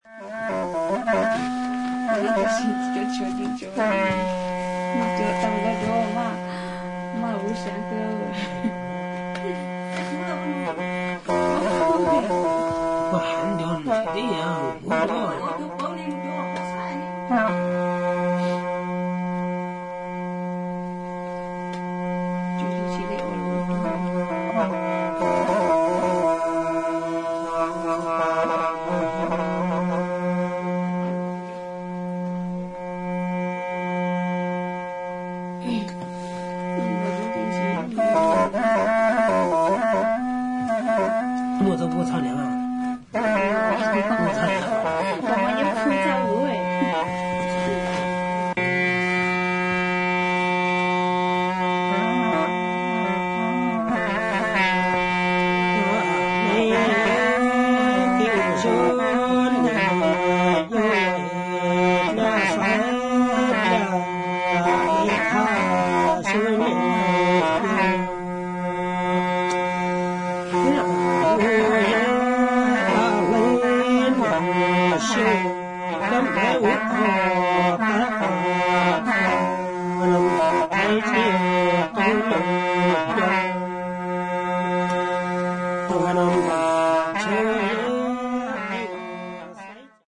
ひょうたん笛、笙、伝統的な弦楽器による音色や人々による唱法が独特の音の調和を生み出している素晴らしいフィールドレコーディング作品。